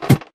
ladder4.ogg